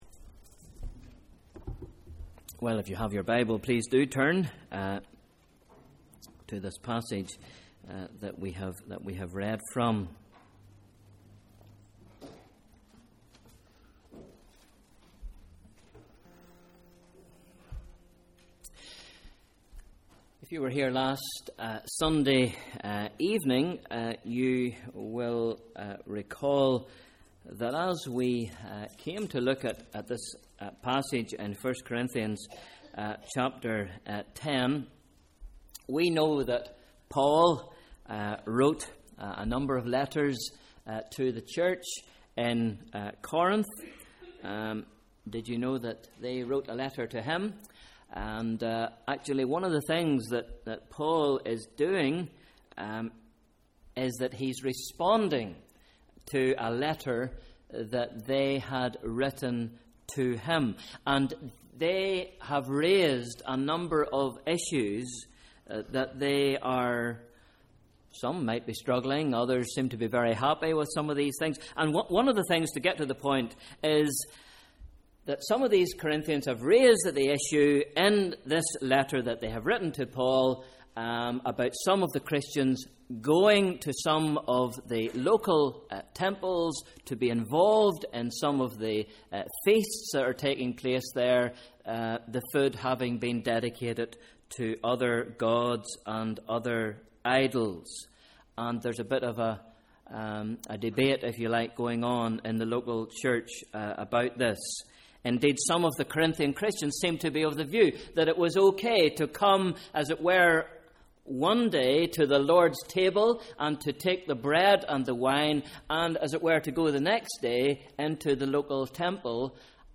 Evening Service: Sunday 12th May 2013